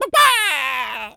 chicken_cluck_scream_long_06.wav